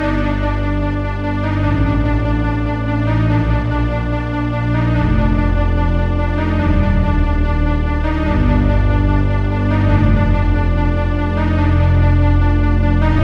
145_pill_D_strings.wav